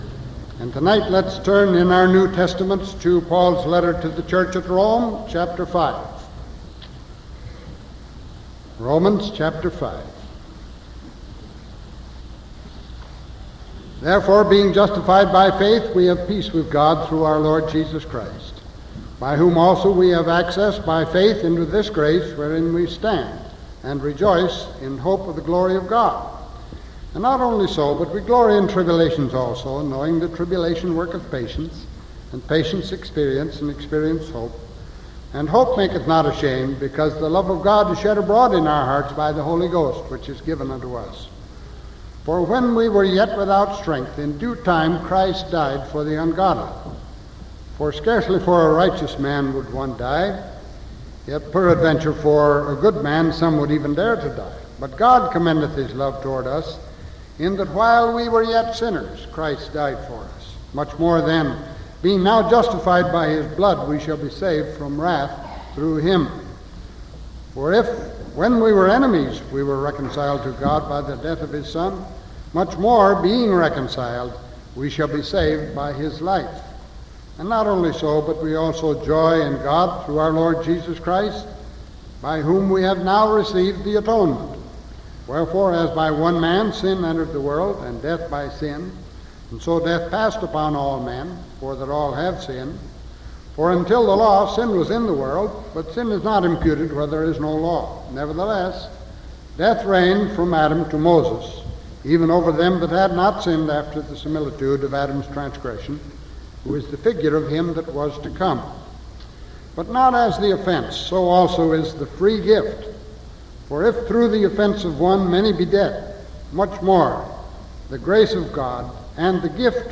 Sermon from October 27th 1974 PM